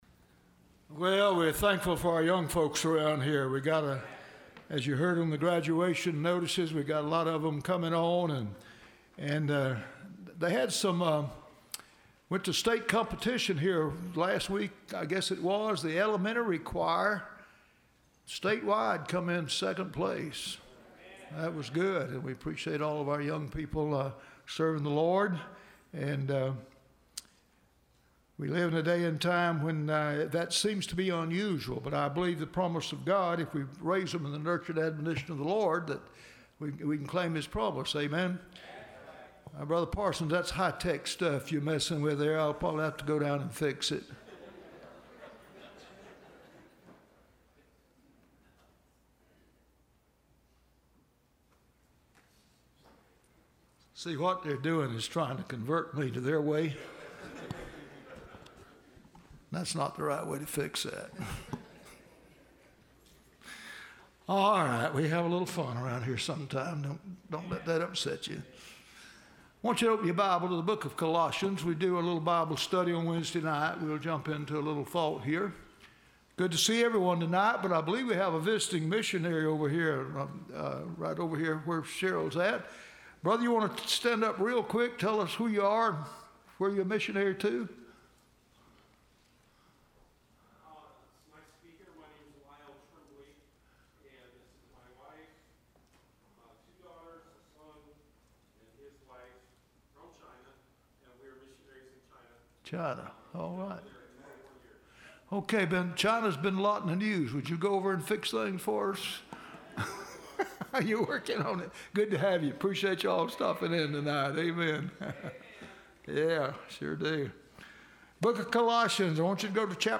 Listen to Message
Service Type: Wednesday